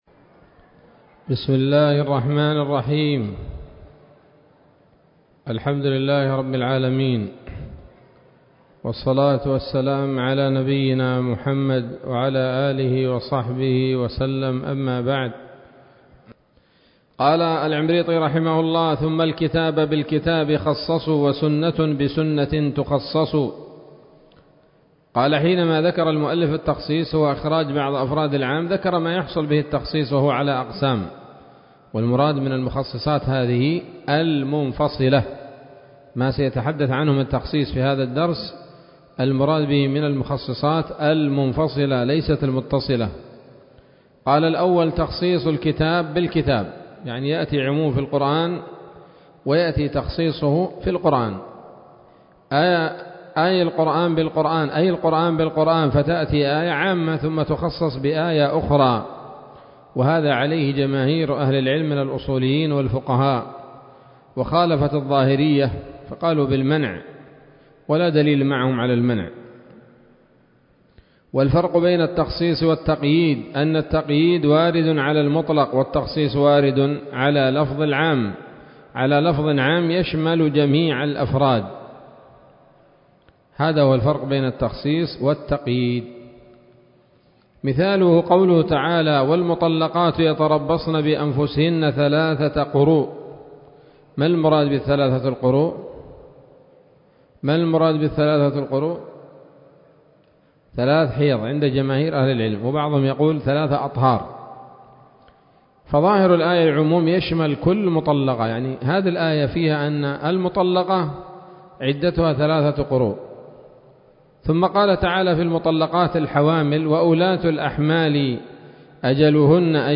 الدرس الثالث والأربعون من شرح نظم الورقات للعلامة العثيمين رحمه الله تعالى